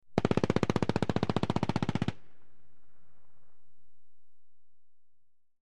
Ak-21 Machine Gun Burst From Distant Point of View, X5